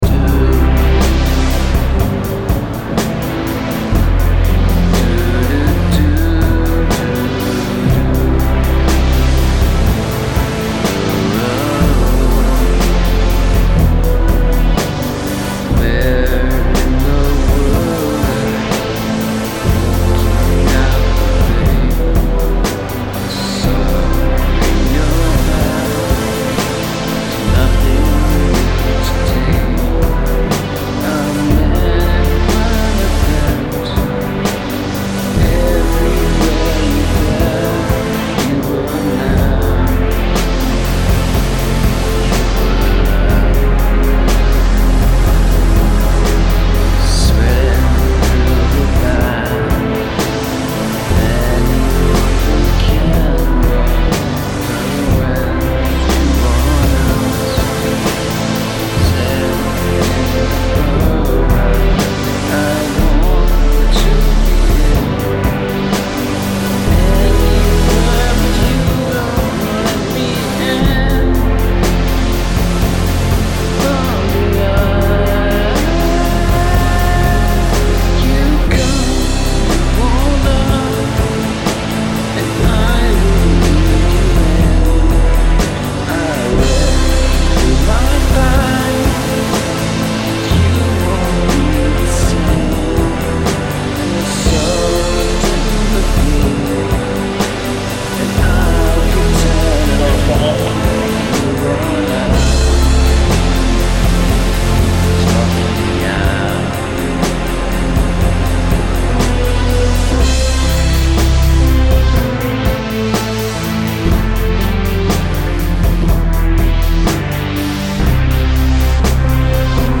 The vocal performance features cameo appearances by one of my co-workers on the radio and the sound of Projector #8. I used the microphone built into the laptop to record the vocal. Frankly, I'm surprised it is listenable at all.
As to the structure, it repeats the same two basic chord structures but I changed the vocal melody from a "verse" to a "chorus" when appropriate.
Enjoy this super rough cut, with the usual wordless vocal improvisation.